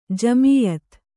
♪ jamīyat